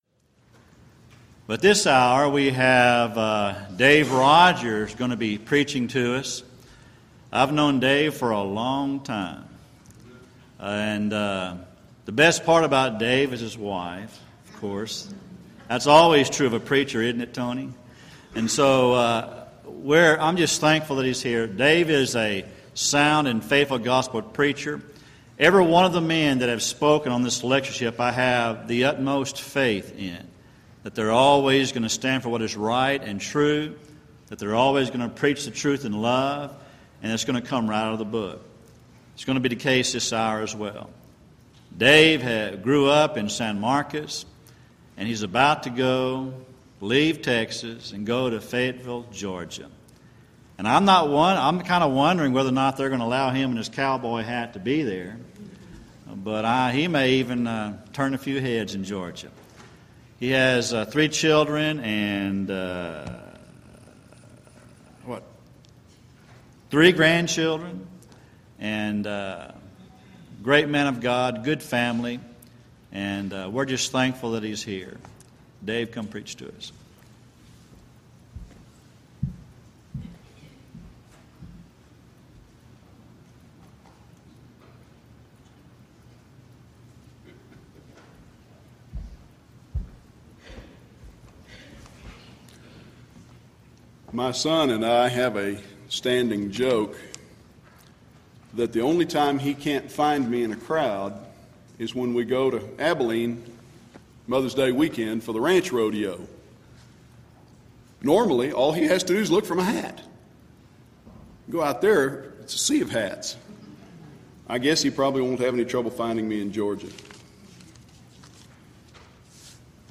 Series: Back to the Bible Lectures Event: 3rd Annual Back to the Bible Lectures